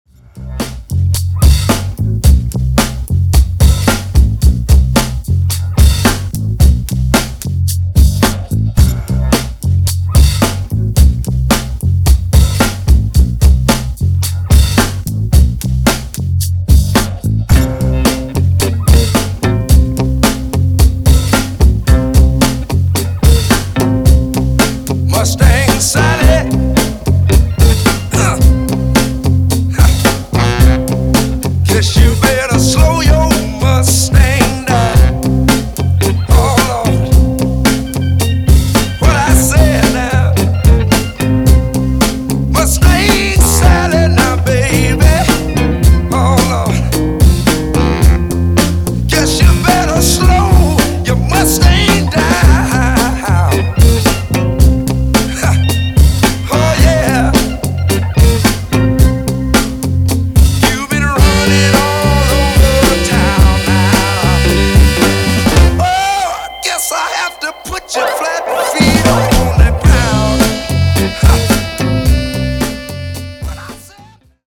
Genres: 60's , RE-DRUM
Clean BPM: 110 Time